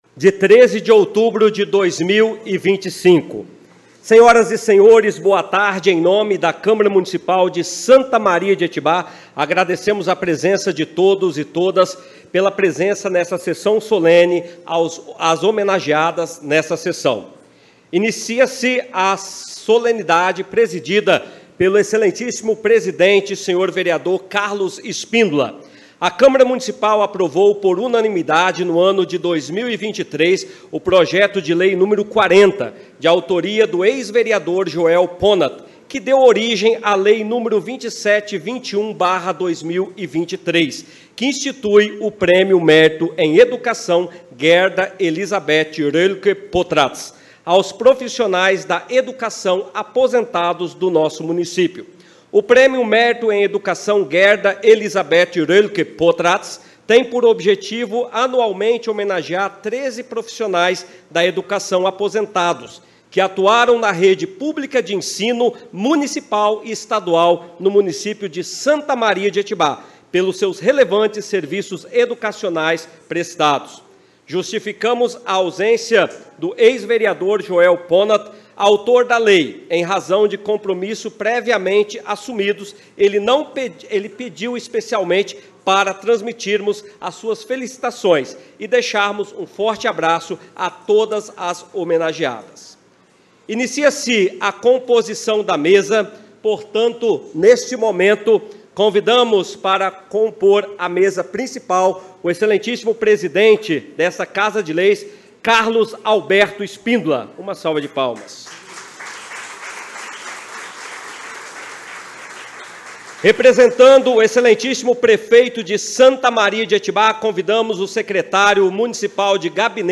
Hino Nacional brasileiro e Hino do Município
sessao-solene-no-5-2025